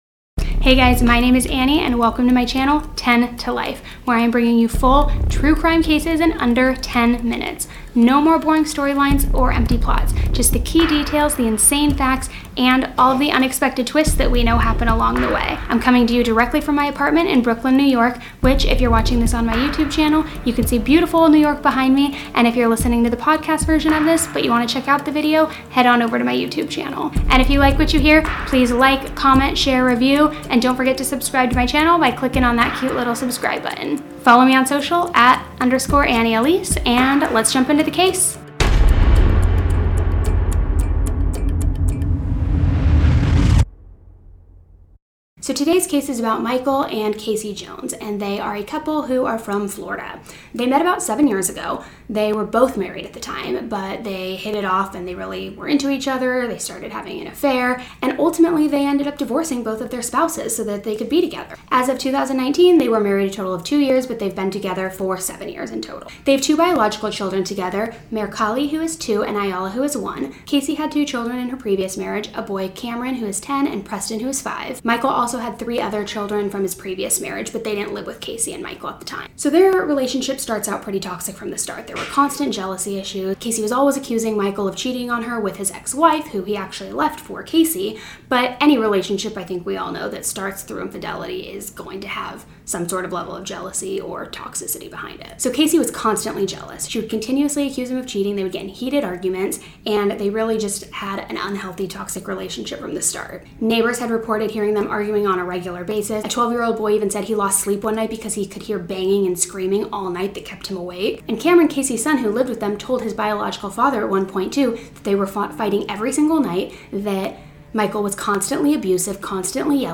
Even better, you’ll hear the story the way you’d want to: like your best friend is filling you in.